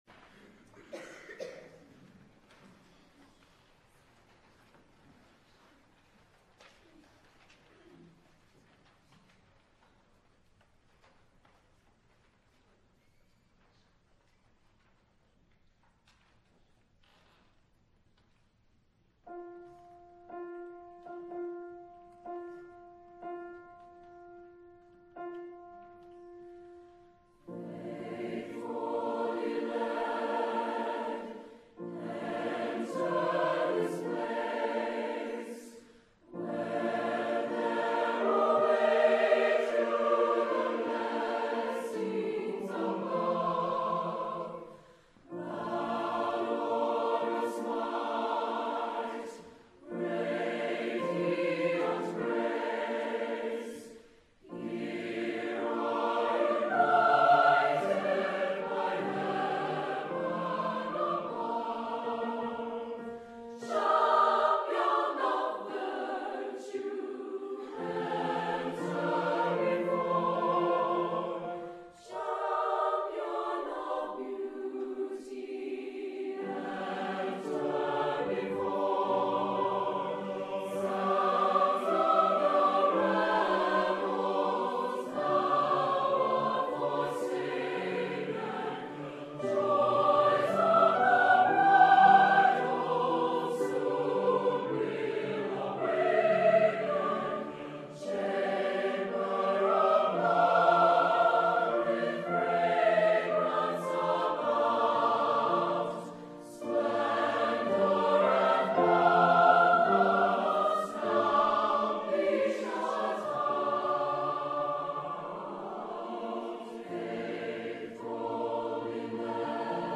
Four-Part Variable Voicing with Piano
Choir